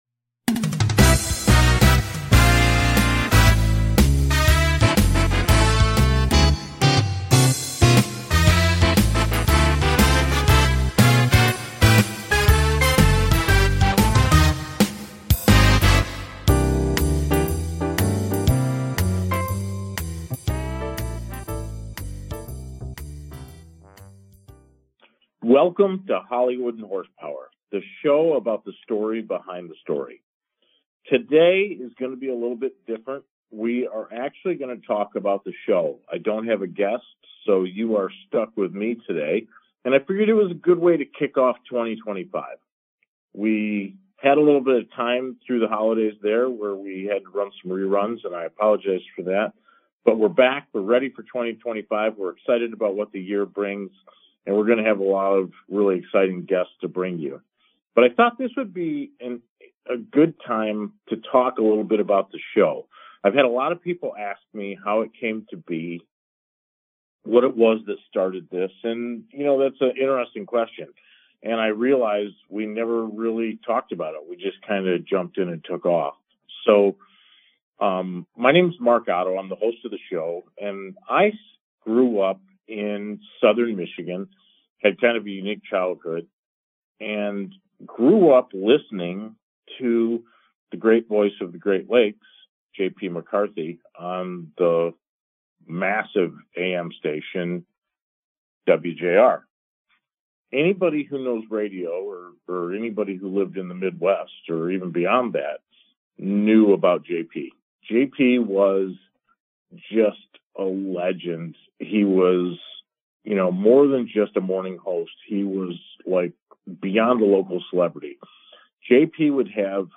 It is where SNL meets The Tonight Show; a perfect mix of talk and comedy.